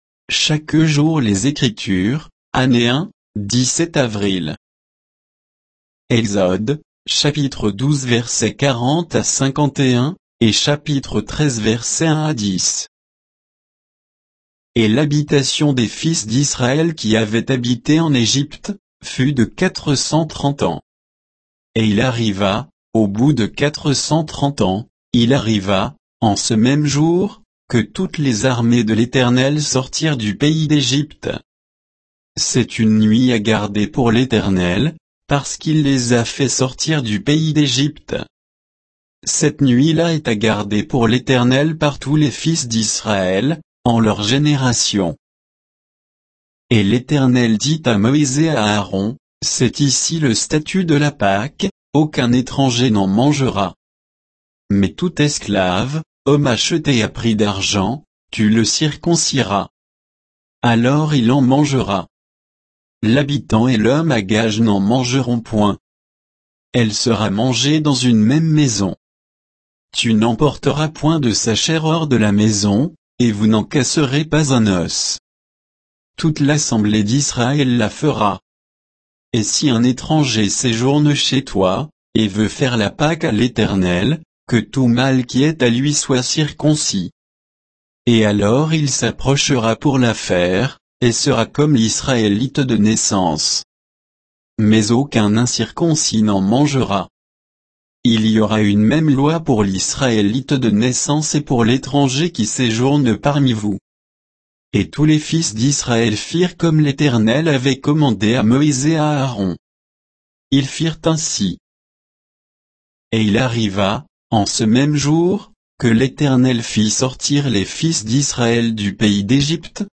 Méditation quoditienne de Chaque jour les Écritures sur Exode 12, 40 à 13, 10